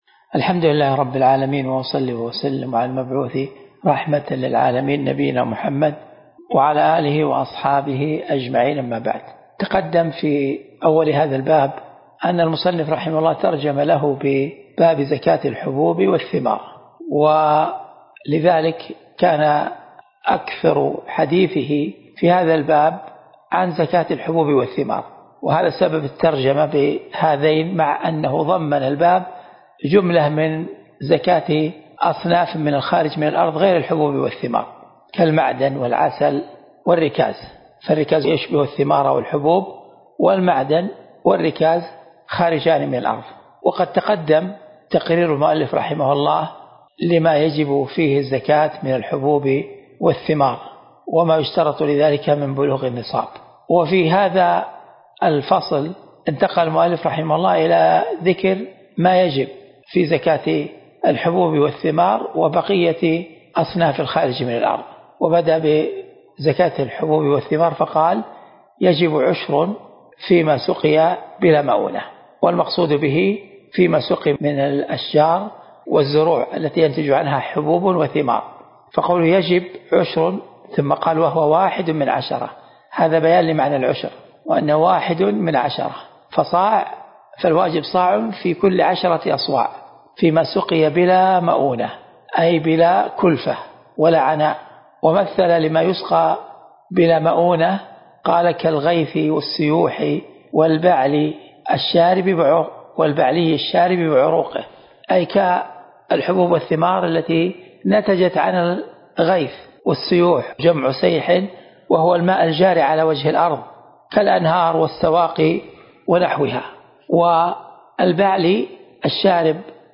الدرس (9) من شرح كتاب الزكاة من الروض المربع